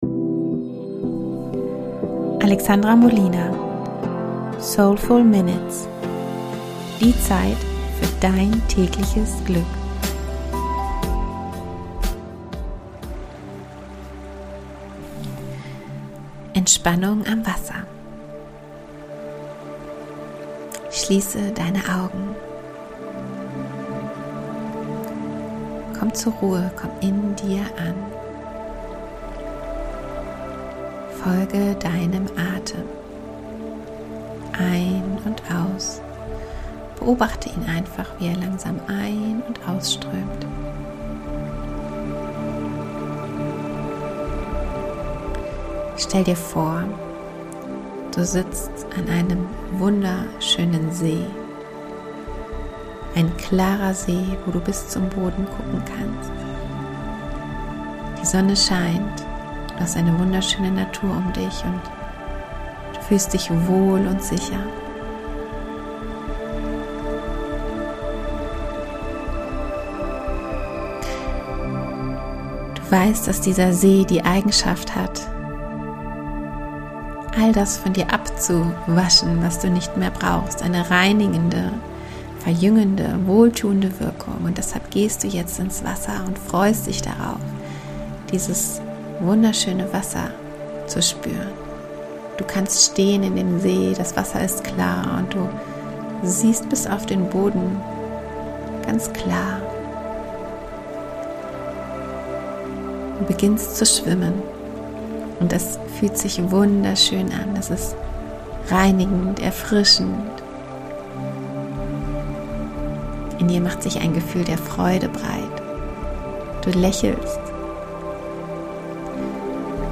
Meditation "alles im Fluss"